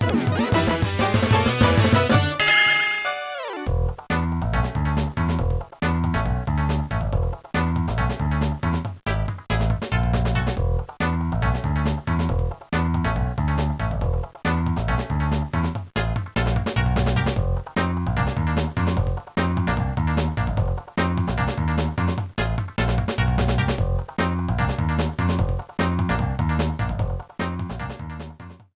Clipped to 30 seconds and applied fade-out.